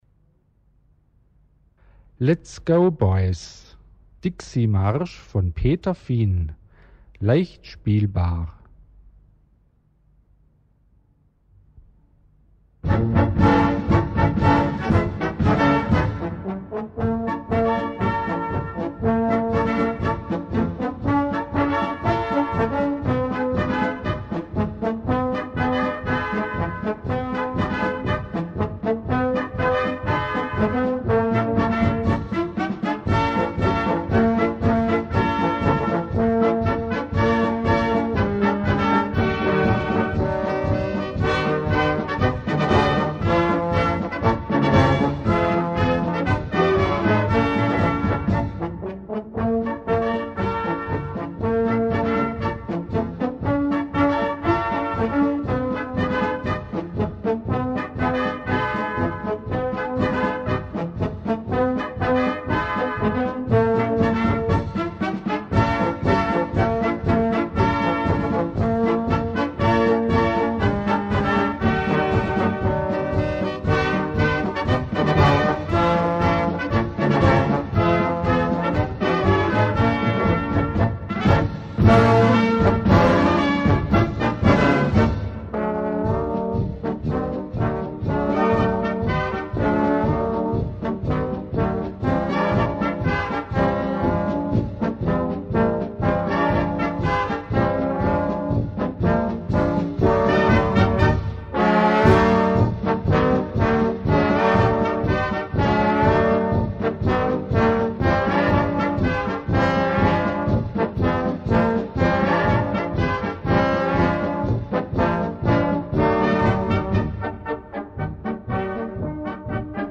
Gattung: Marsch
Besetzung: Blasorchester
mit Swingeinschlag.